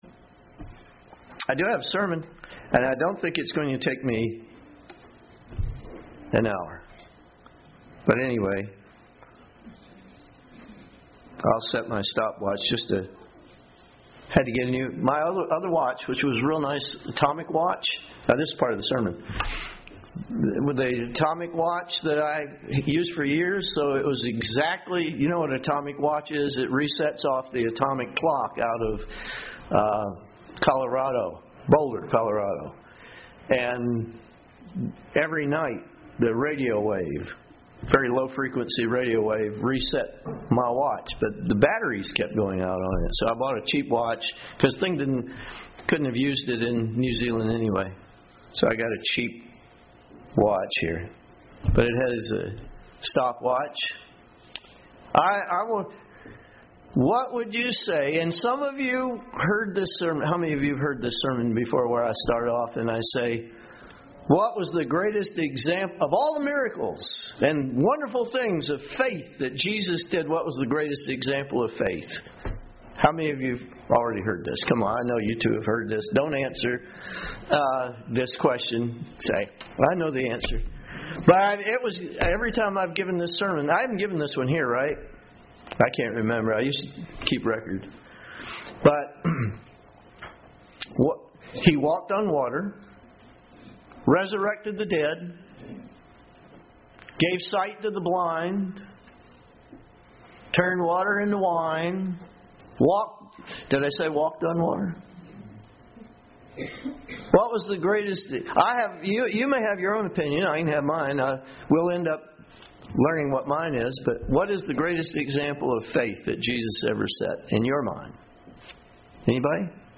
Habakkuk cries out to God about the injustice and iniquity he sees in the nation of Judah. God's answer is not quite what he expected or wanted, but he learns to accept God's decision by the end of the book. This sermon provides a good overview of the message found in the book of Habakkuk.
Given in Murfreesboro, TN